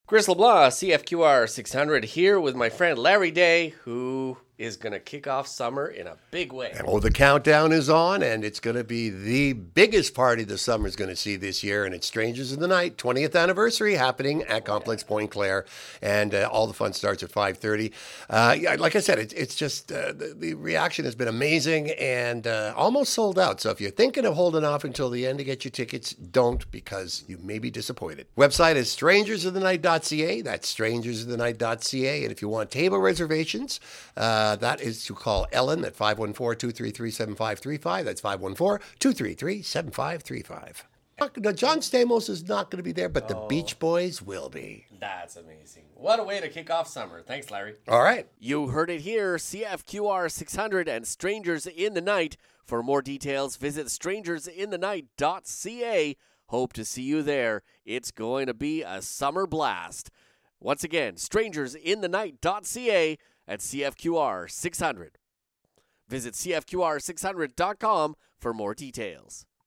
Strangers In The Night Interview